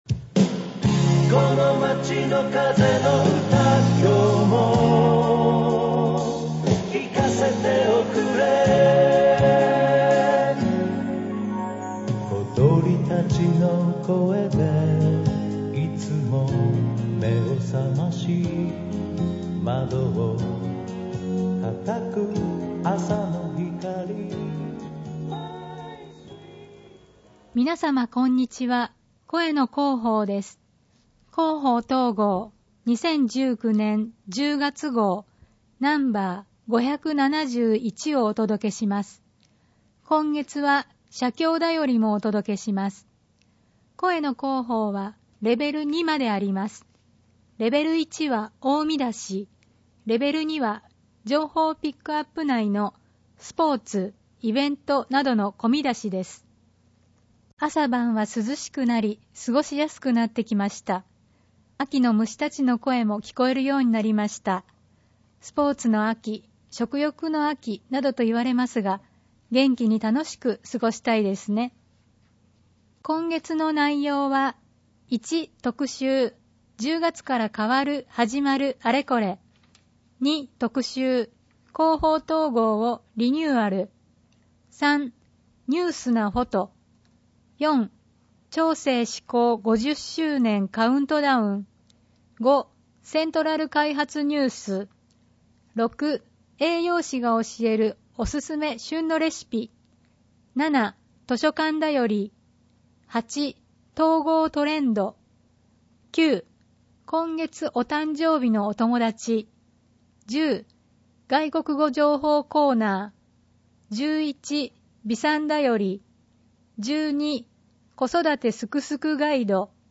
広報とうごう音訳版（2019年10月号）